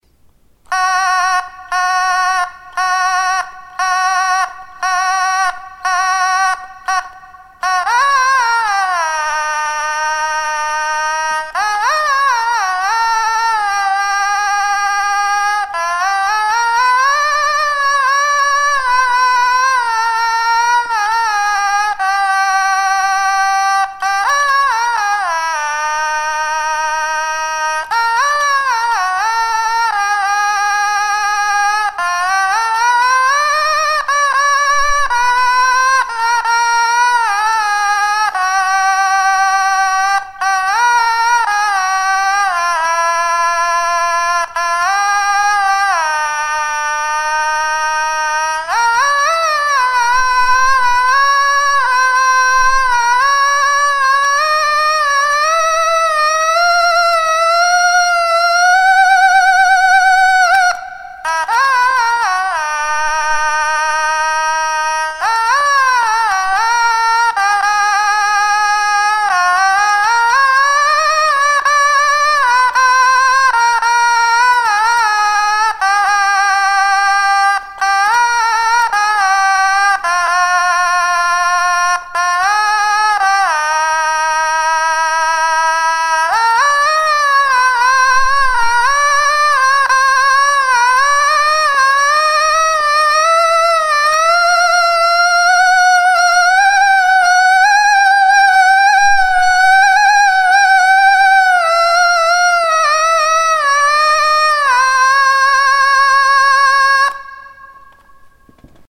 ビブラートすごっ！
演奏スタイルは違うけど一本指奏法だ！一本指奏法を極めたいわたしとしては1音1音粒だっているの見習いたい